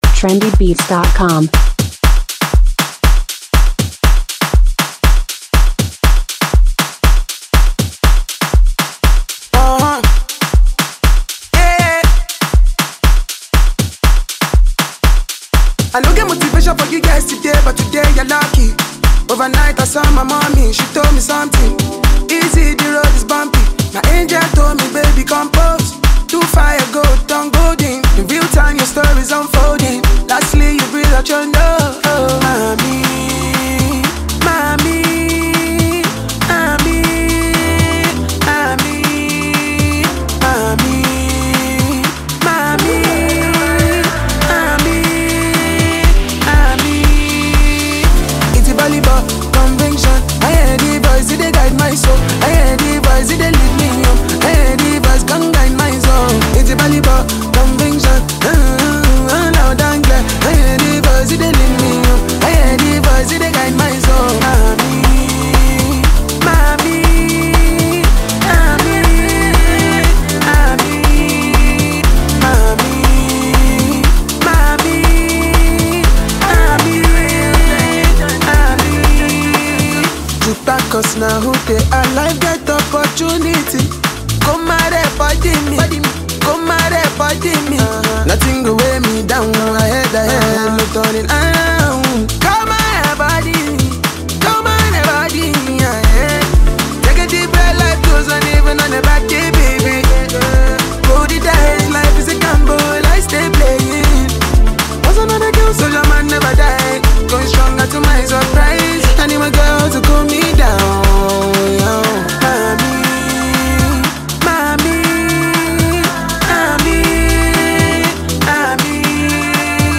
Afro-pop
backed by melodic instrumentals
smooth vocals